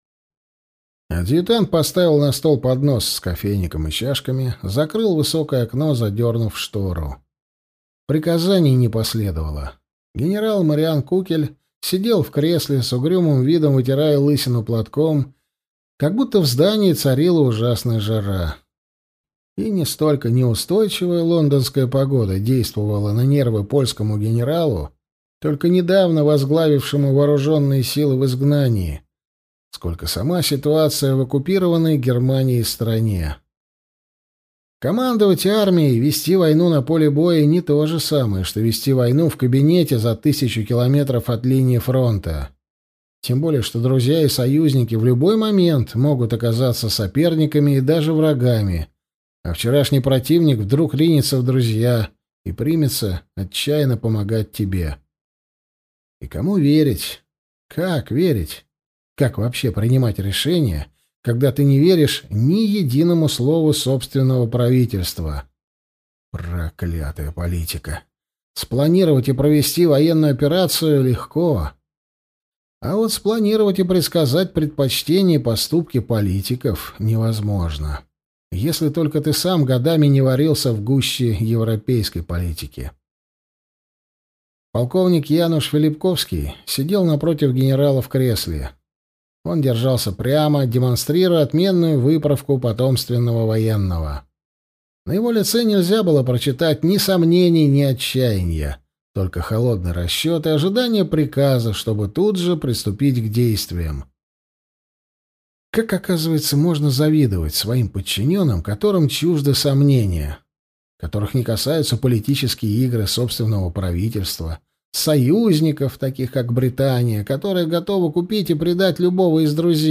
Аудиокнига Боевые асы наркома | Библиотека аудиокниг
Прослушать и бесплатно скачать фрагмент аудиокниги